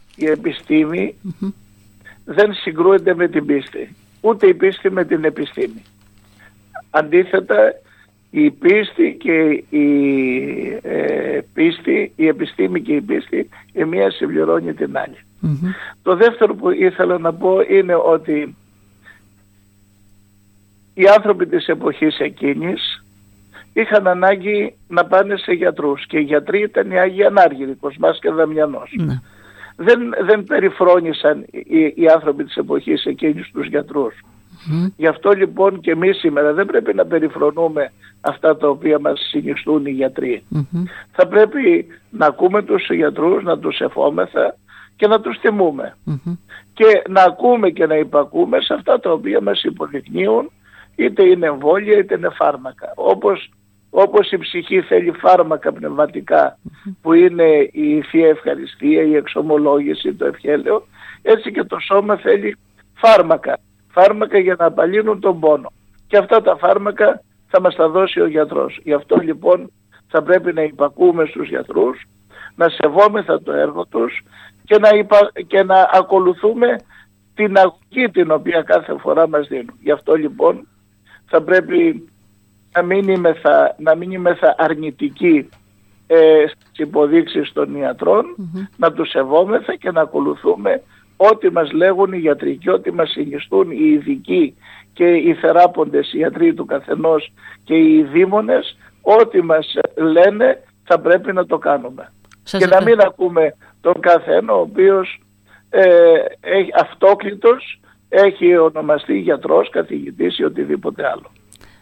Σε δηλώσεις του στην ΕΡΤ Ορεστιάδας μεταξύ άλλων τόνισε ότι «η επιστήμη δεν συγκρούεται με την πίστη , ούτε η πίστη με την επιστήμη. Αντιθέτως συνέχισε, η μια συμπληρώνει την άλλη»